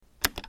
button.ogg